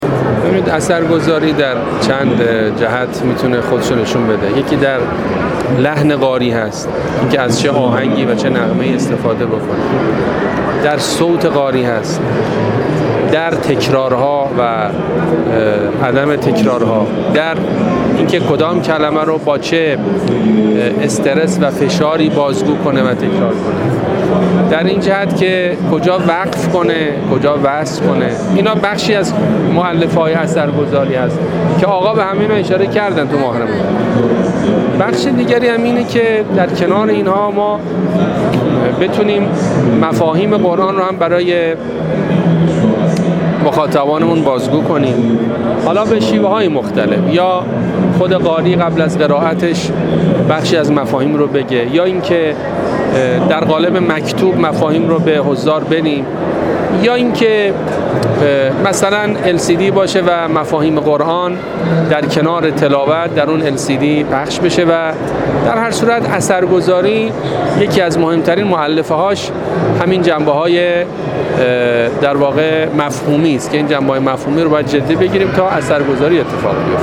حجت‌الاسلام والمسلمین محمد حاج‌ابوالقاسم، عضو مجلس خبرگان رهبری، در گفت‌وگو با ایکنا، درباره تلاوت‌ اثرگذار گفت: اثرگذاری در چند جهت خود را نشان می‌دهد؛ یکی در لحن قاری است؛ اینکه از چه آهنگ و نغمه‌هایی استفاده کند.